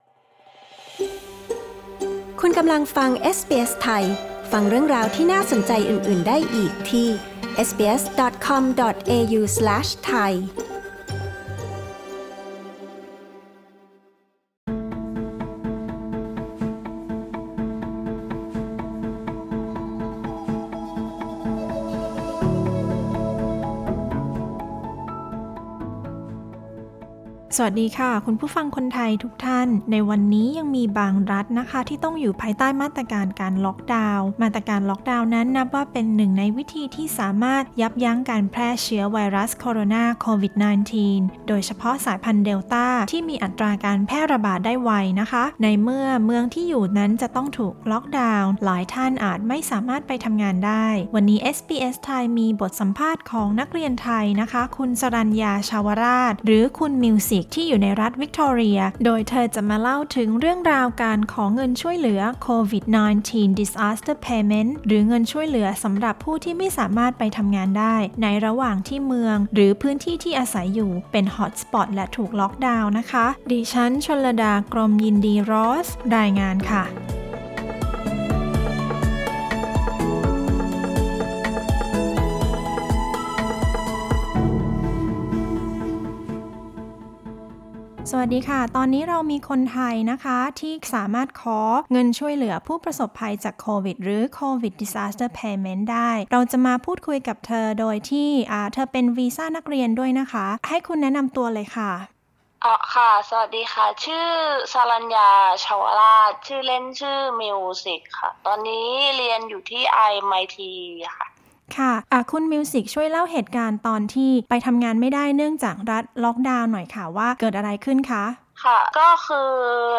interview-covid_payment-final.mp3